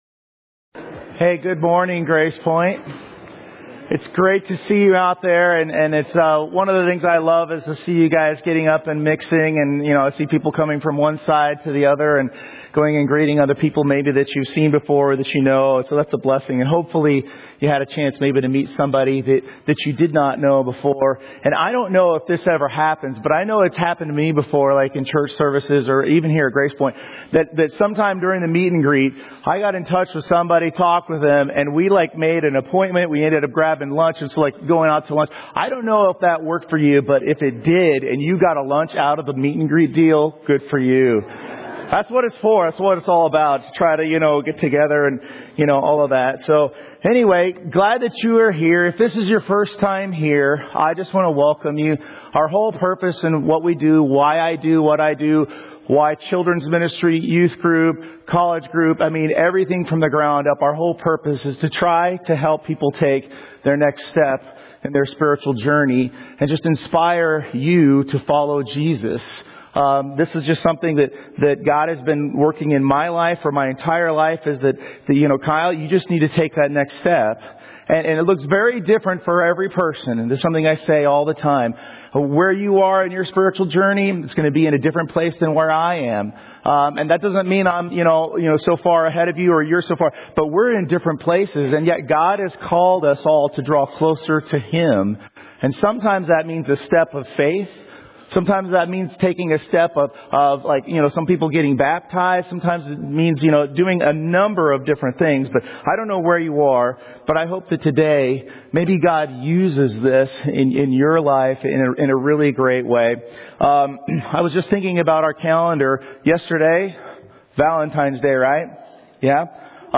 2026 Sermons